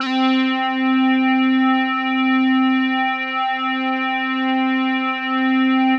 C4_trance_lead_2.wav